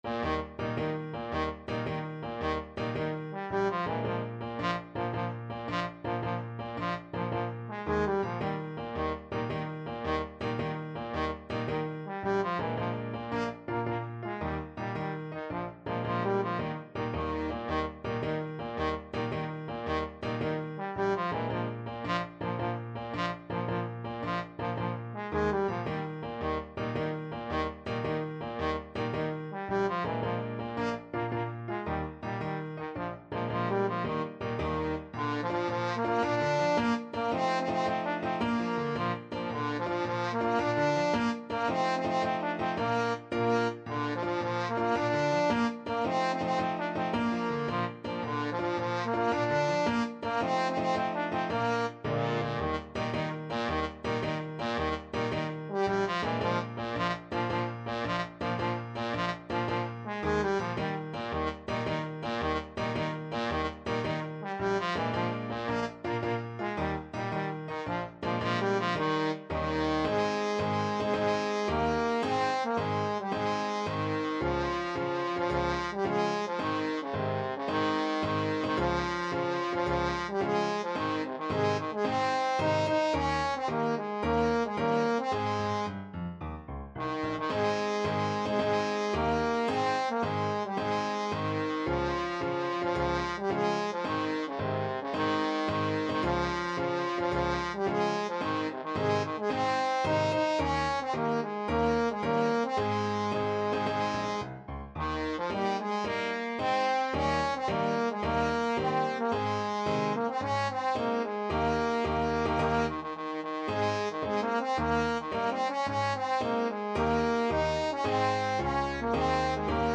Trombone
Eb major (Sounding Pitch) (View more Eb major Music for Trombone )
6/8 (View more 6/8 Music)
Classical (View more Classical Trombone Music)
piefke_koniggratzer_marsch_TBNE.mp3